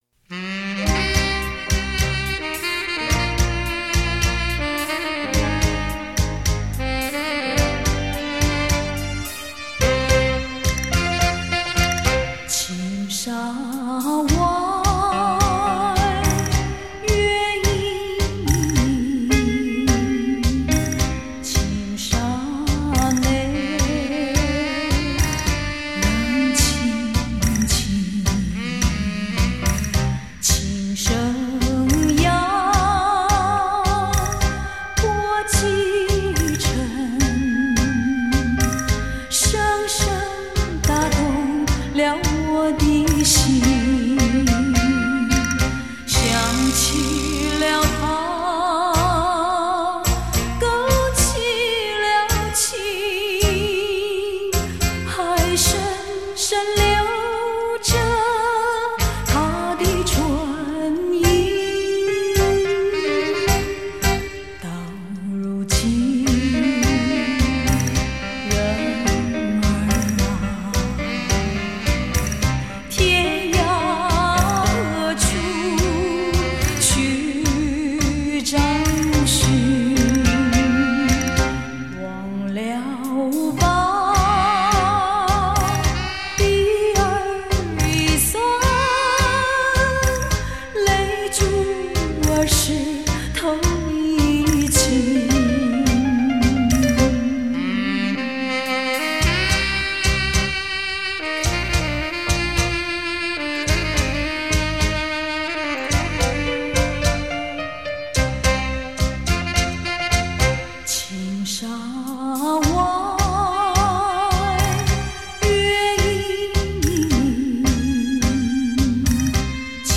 流行皇后 甜歌精品 至情至圣 精心奉献
探戈